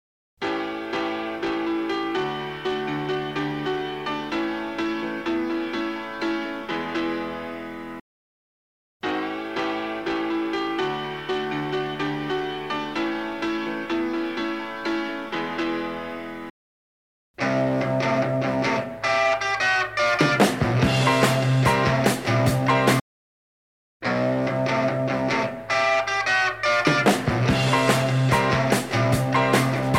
an obvious 60cycle hum in the middle
during a particularly quiet section